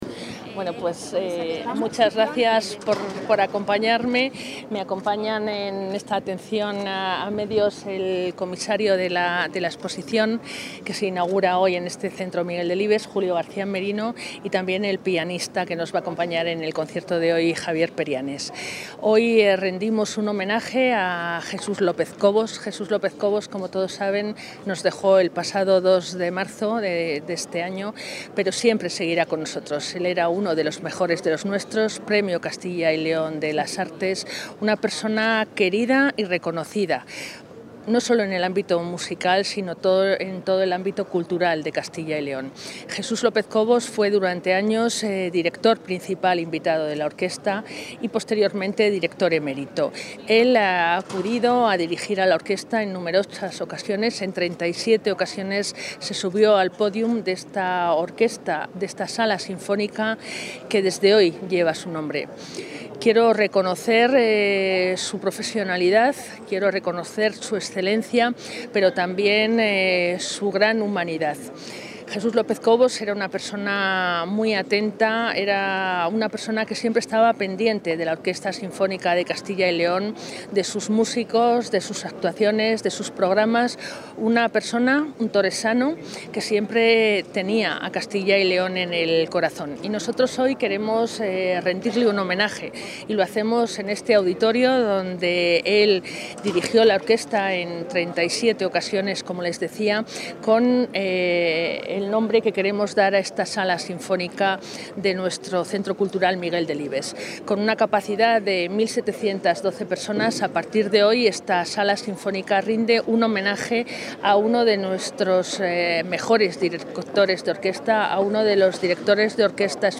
Audio consejera.